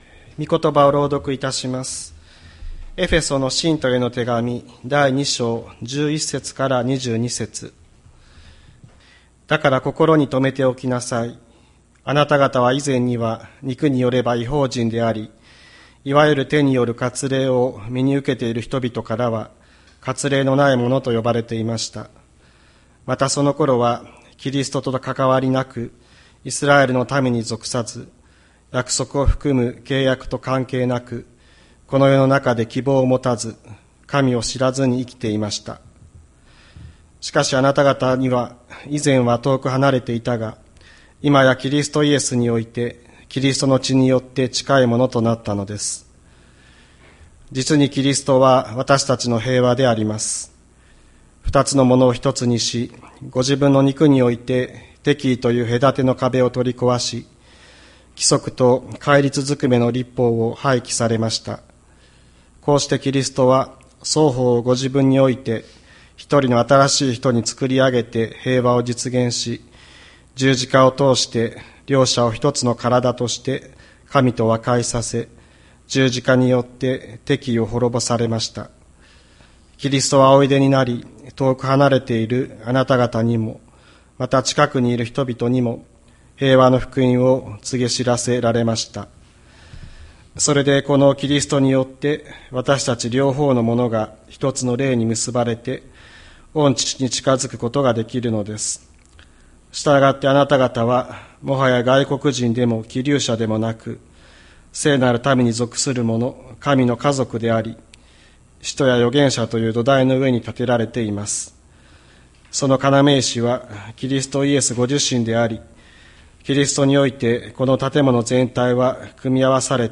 2024年08月11日朝の礼拝「キリストこそ平和」吹田市千里山のキリスト教会
千里山教会 2024年08月11日の礼拝メッセージ。